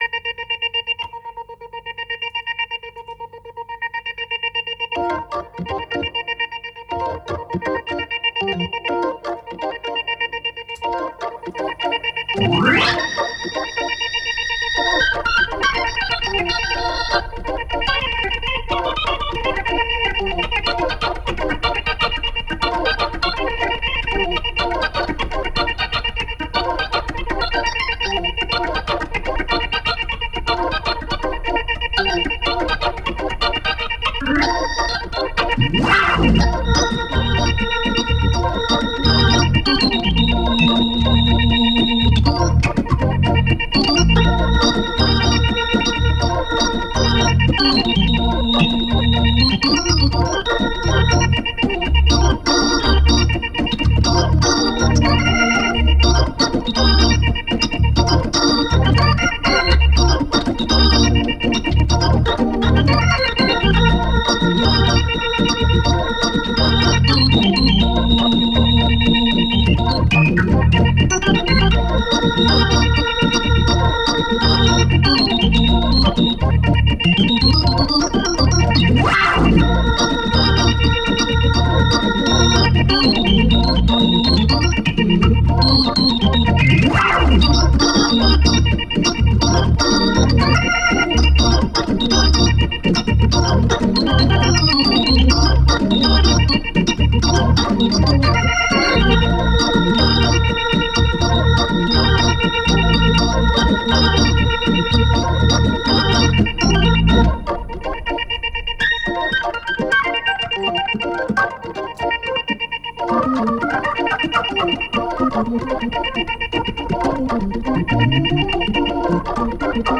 One gets quite a solemn feeling sitting there in the middle.
Hammond organ, 4 leslies
Genres: Experimental